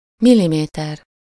Ääntäminen
Ääntäminen Paris: IPA: [mi.li.mɛtʁ] France (Paris): IPA: /mi.li.mɛtʁ/ Haettu sana löytyi näillä lähdekielillä: ranska Käännös Ääninäyte 1. milliméter Suku: m .